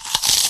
zombieAttack.ogg